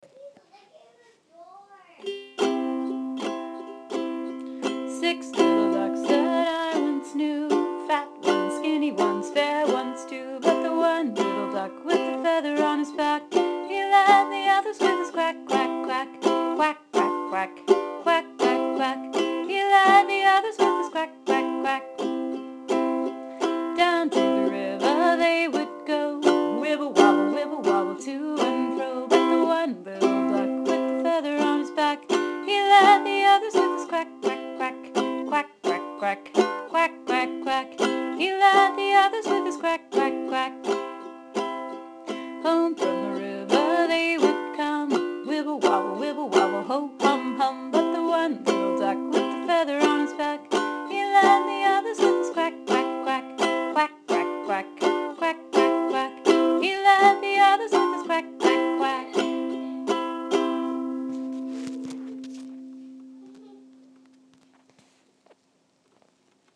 I’ve done this song for years, and it’s a popular favorite at toddler and baby storytimes.
Here are the lyrics, with the accompanying ukulele or guitar chords: